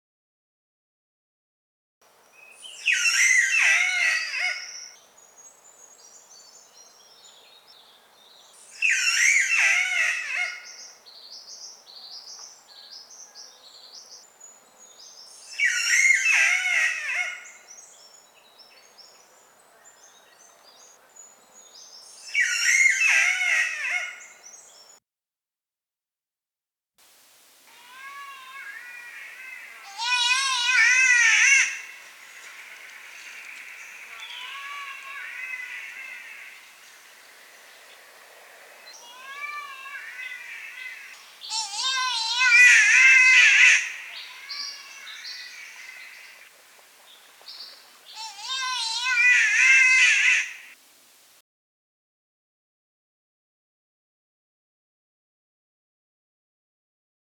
Unique Australian Bird Sounds
green catbird
13-green-catbird.mp3